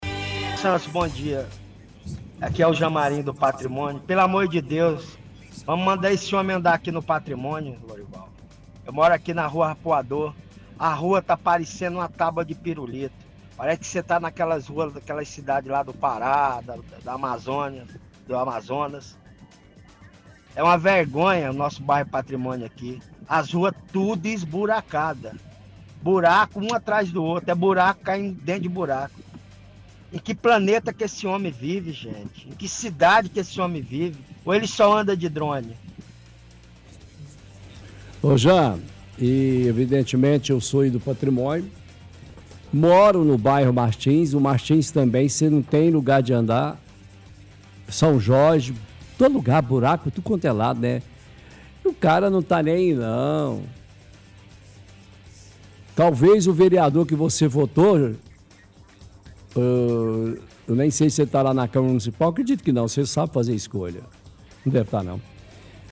– Ouvinte reclama que o bairro Patrimônio, R. Arpoador está cheio de buraco.